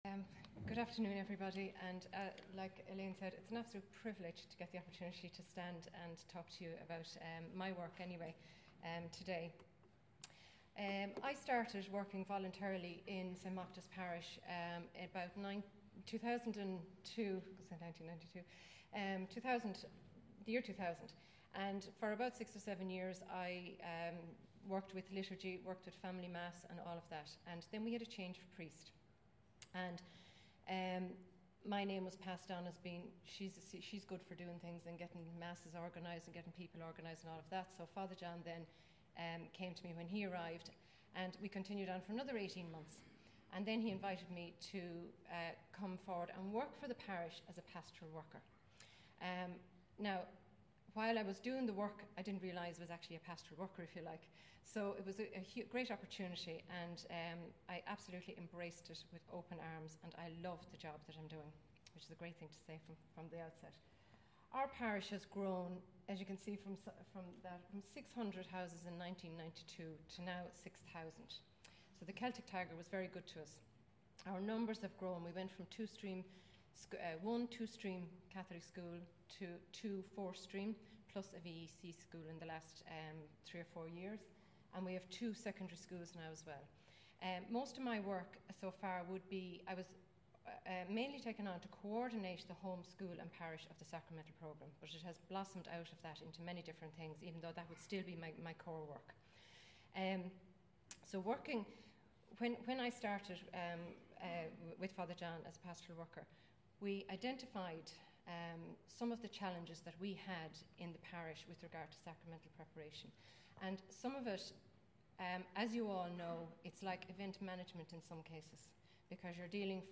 Meath Diocesan Assembly 24 iv 2012